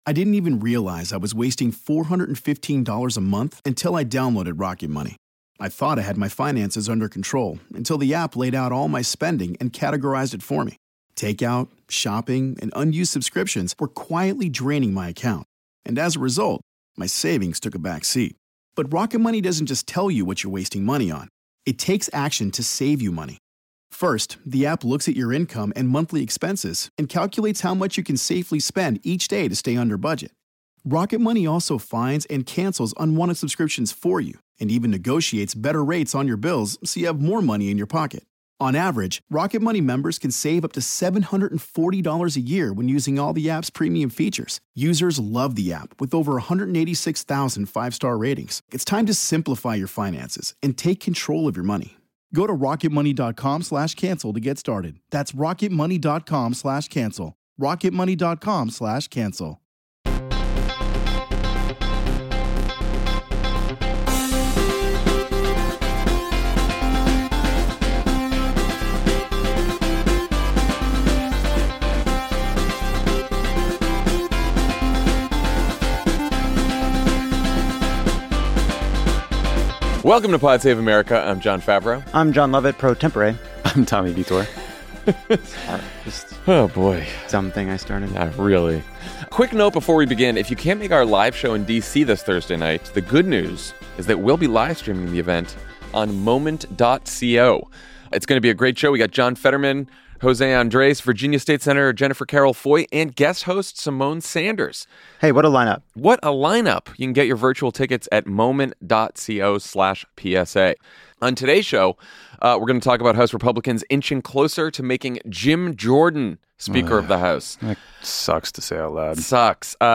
Republicans inch closer to making election-denier Jim Jordan Speaker. House Minority Leader Hakeem Jeffries joins to discuss the Democrats’ plan to address the chaos in Congress